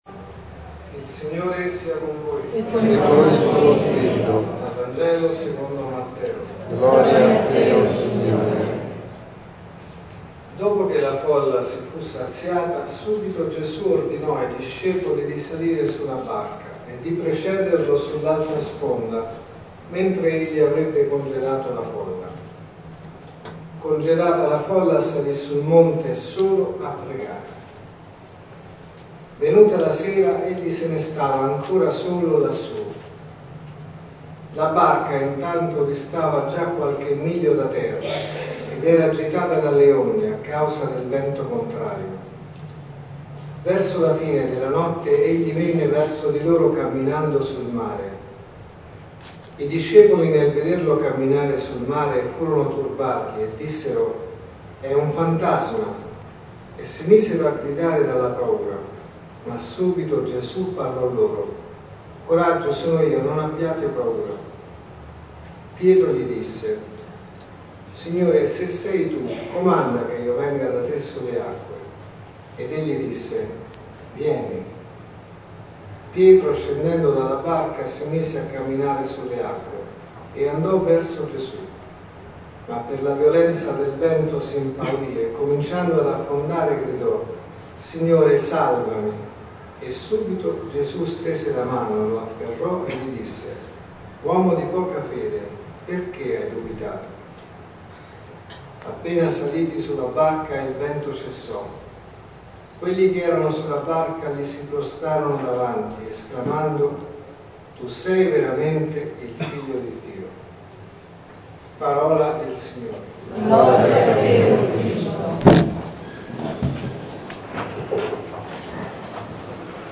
| Omelie LETTURE: Vangelo, Prima lettura e Seconda lettura Alleluia, alleluia.